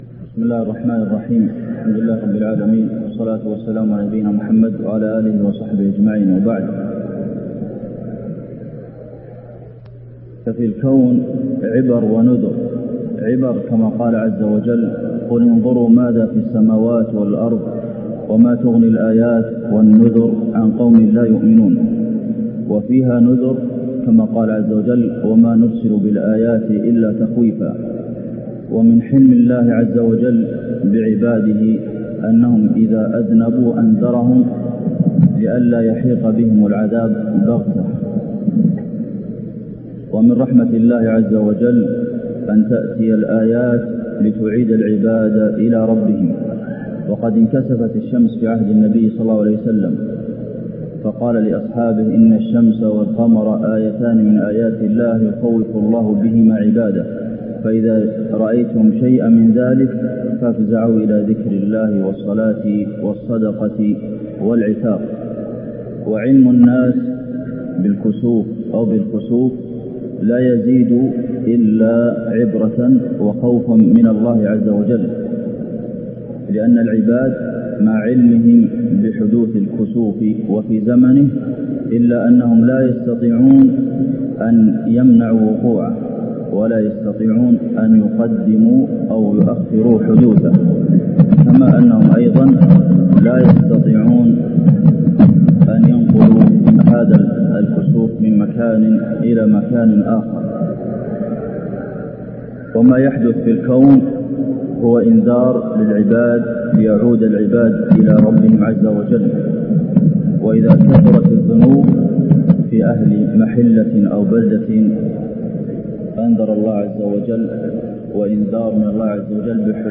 خطبة الكسوف المدينة - الشيخ عبدالمحسن القاسم
تاريخ النشر ٢٩ شعبان ١٤٢٦ هـ المكان: المسجد النبوي الشيخ: فضيلة الشيخ د. عبدالمحسن بن محمد القاسم فضيلة الشيخ د. عبدالمحسن بن محمد القاسم خطبة الكسوف المدينة - الشيخ عبدالمحسن القاسم